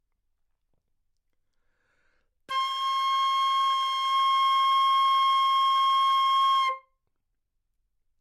长笛单音 " 长笛 C6
描述：在巴塞罗那Universitat Pompeu Fabra音乐技术集团的goodsounds.org项目的背景下录制。
标签： 好声音 C6 单注 多重采样 纽曼-U87 长笛